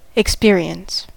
experience: Wikimedia Commons US English Pronunciations
En-us-experience.WAV